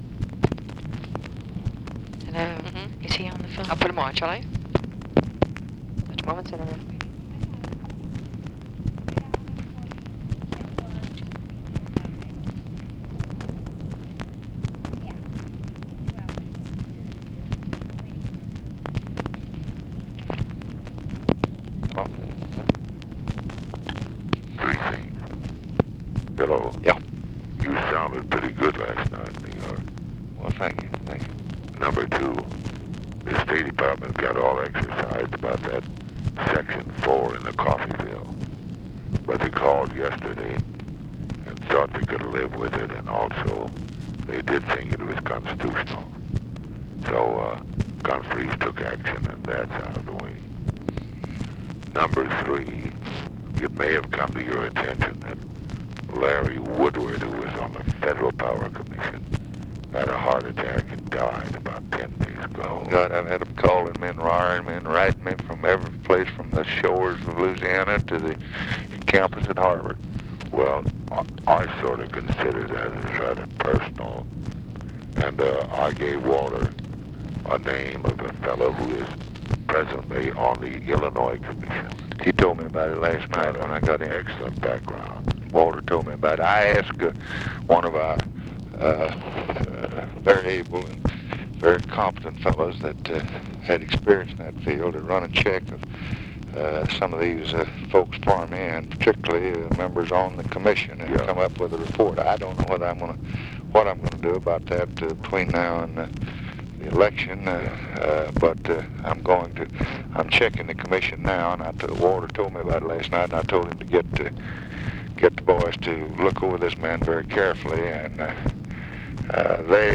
Conversation with EVERETT DIRKSEN, August 13, 1964
Secret White House Tapes